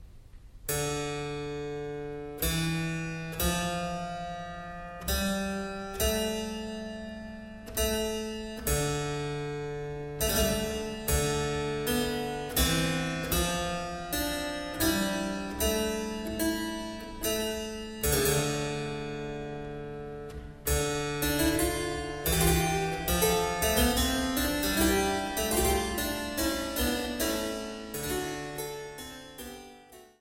Virginal und Cembalo